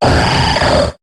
Cri de Kaorine dans Pokémon HOME.